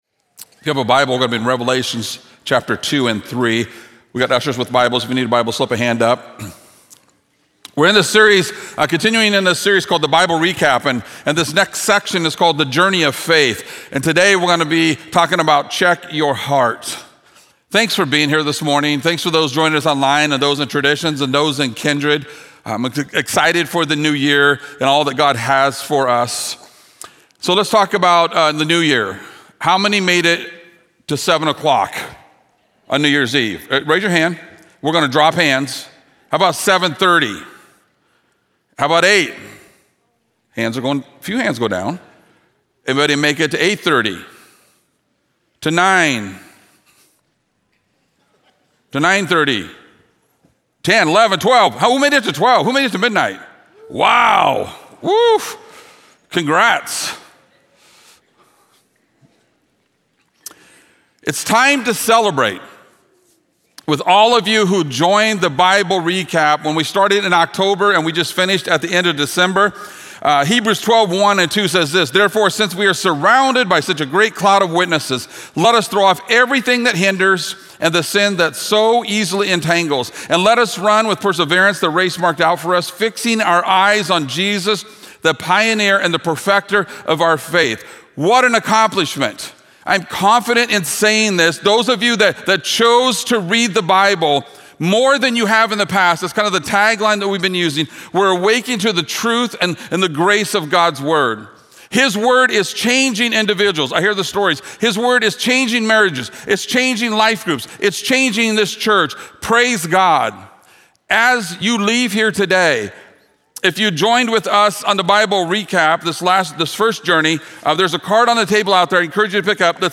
Thank you for joining us today as we continue in our Bible Recap sermon series.